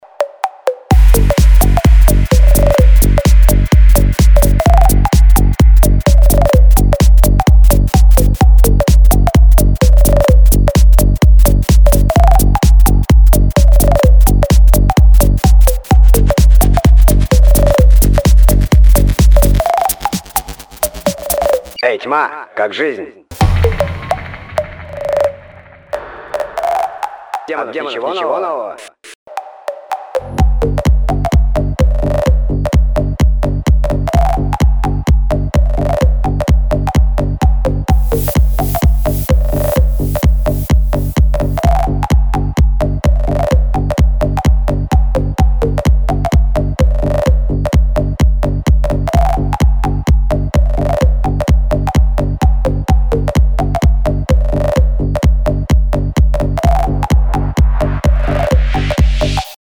• Качество: 256, Stereo
веселые
без слов